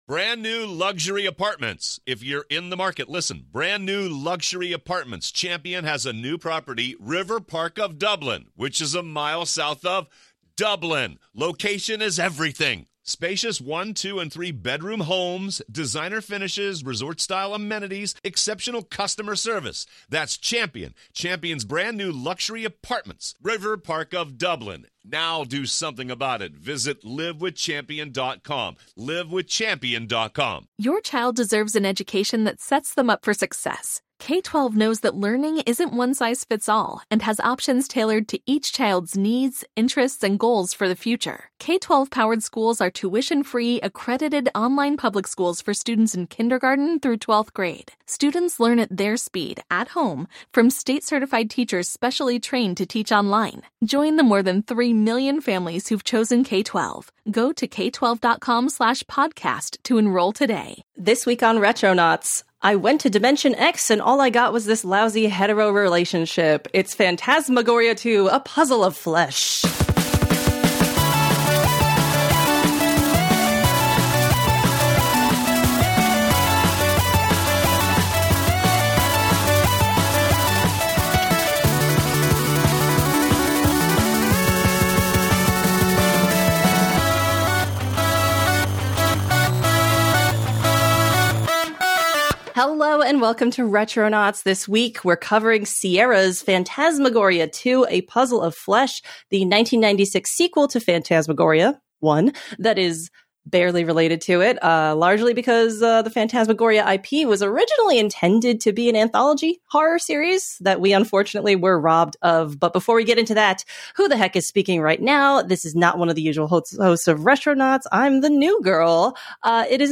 chats with two of its actors